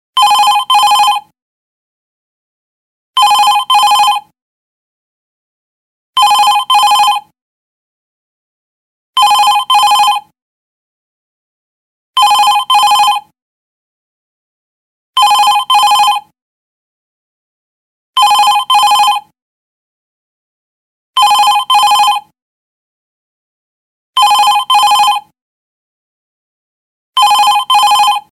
Категория: звуки